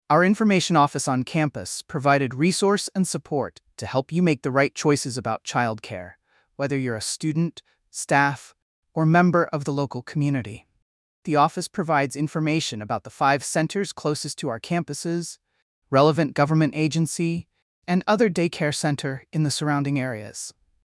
Sample answer: